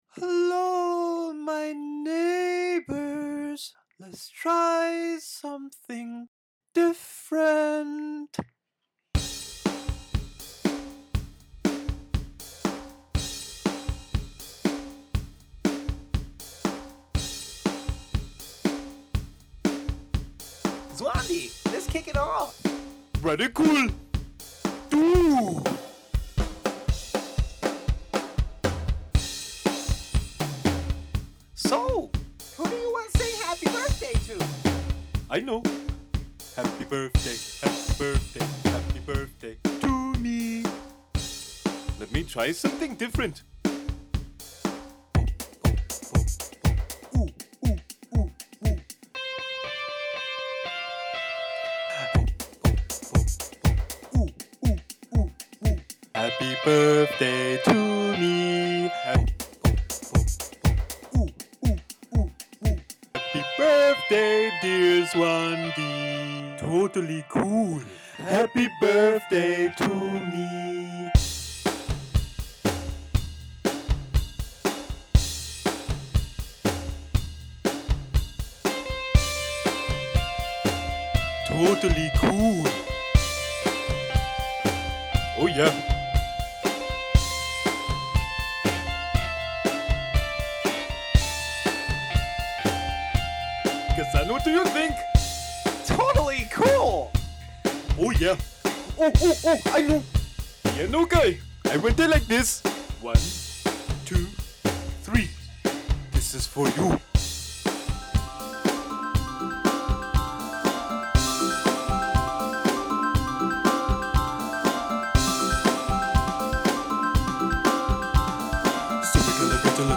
In this imaginary land full of Happy Birthdays, 4 characters always sing happy birthday everywhere to everyone.
There were 4 characters I voiced;
Everything was made of pre-made loops I put together, except the guitar.
The guitar is actually a midi played from the piano.
To Americans, Zwandi's Indian accent has charm.